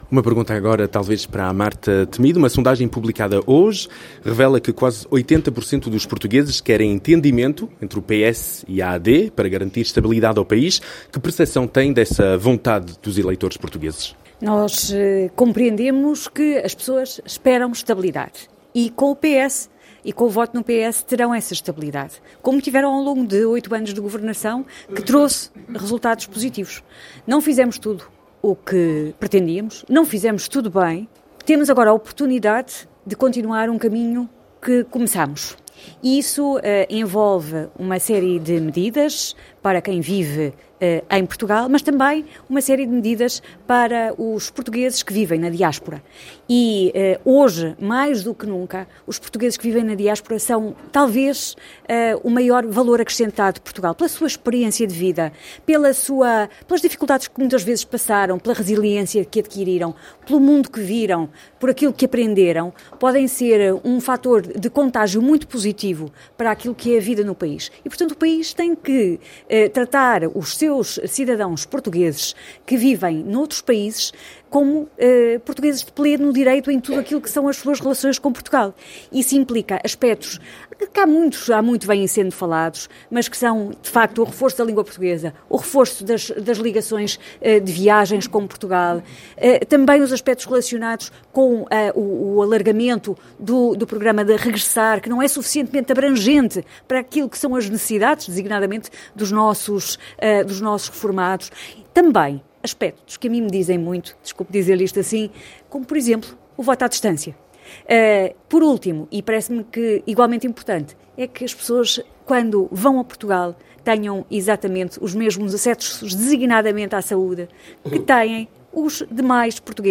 Durante o seu discurso, que se revelou factualmente extenso e retirou tempo precioso à candidata, Marta Temido fez questão de destacar a importância da mobilização comunitária: “É preciso envolver os atores da comunidade.” Alertou para o afastamento crescente entre os portugueses da diáspora e as estruturas políticas: “Temos andado afastados uns dos outros, mas não pode ser. Precisamos de todos.”